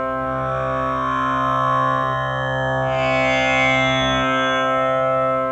SympDroneTambura_87_C.wav